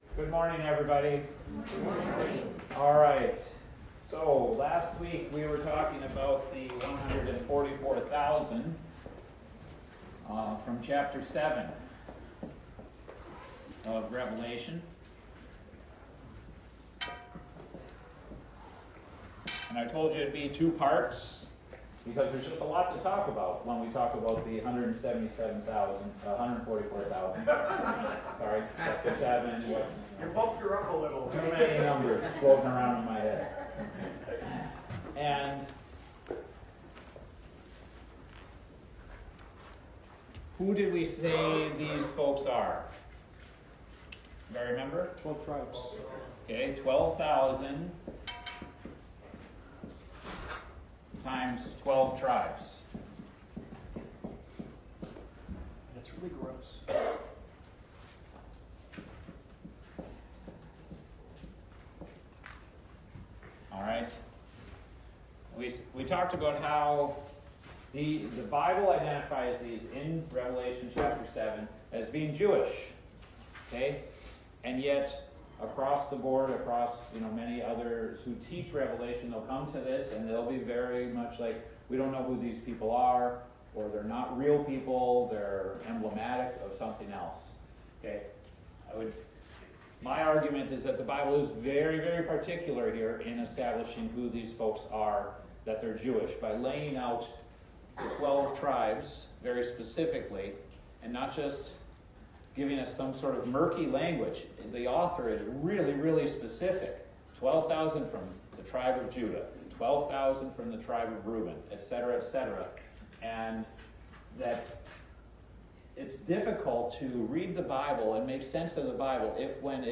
Adult Sunday School A Study in Revelation